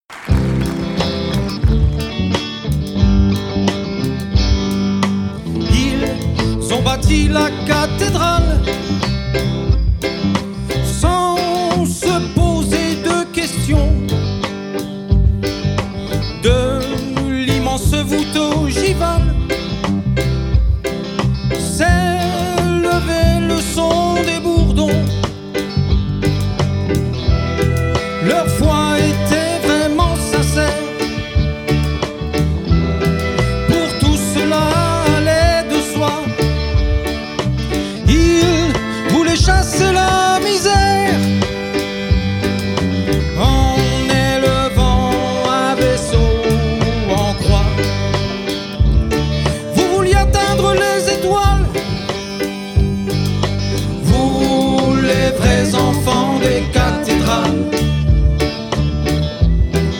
Ce reggae-rock